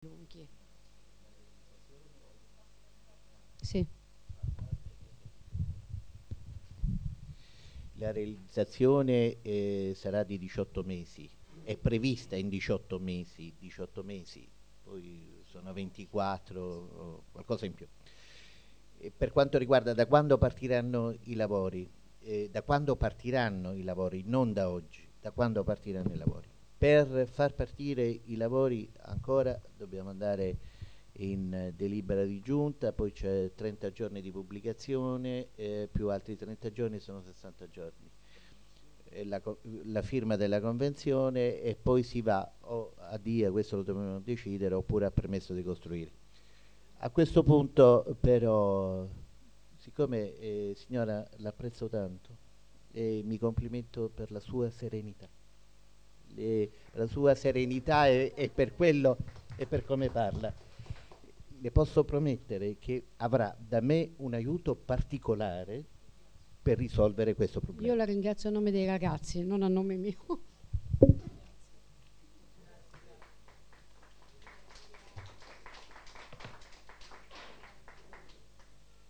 Registrazione integrale dell'incontro svoltosi il 4 ottobre 2012 presso la sala riunioni di Via Boncompagni, 71